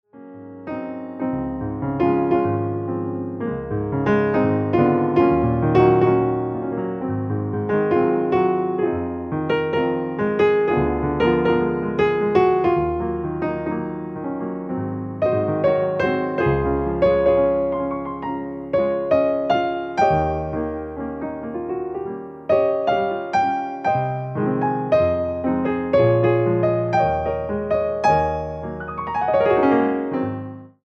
entertainment pianists